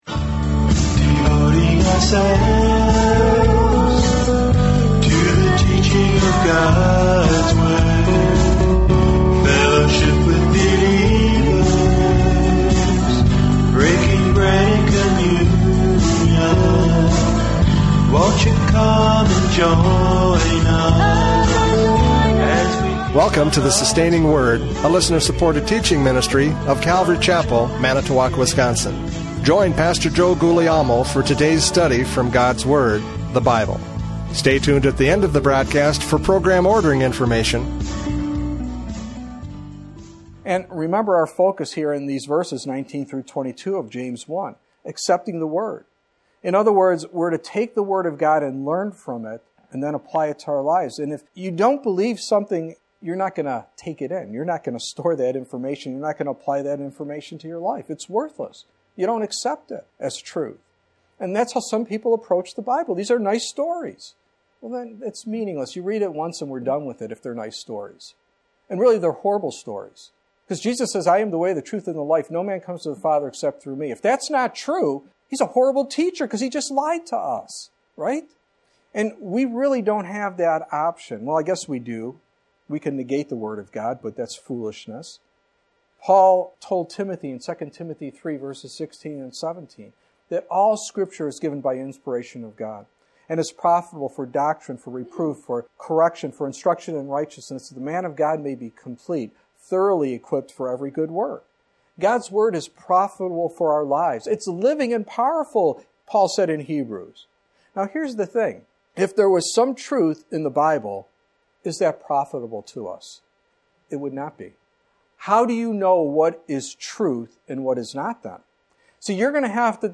James 1:19-27 Service Type: Radio Programs « James 1:19-27 Obedience to the Word!